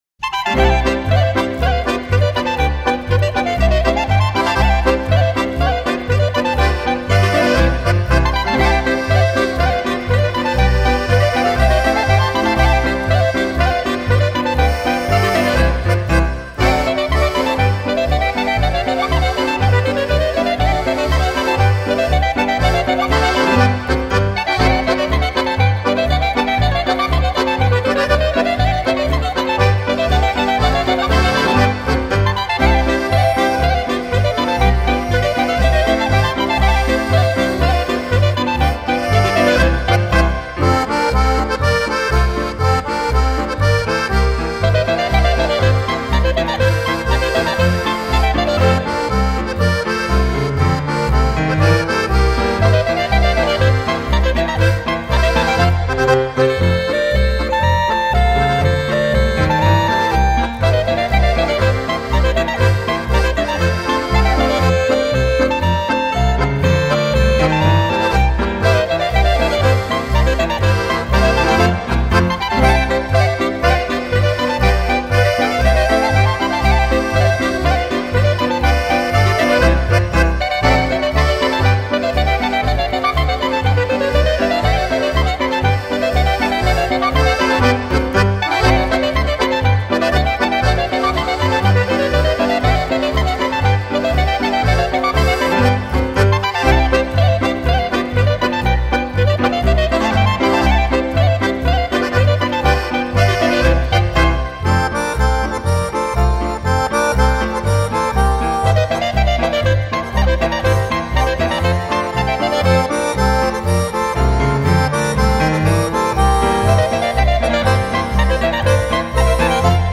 Swiss folk dances
clarinet, saxophone, accordion, piano and double bass
Schottisch.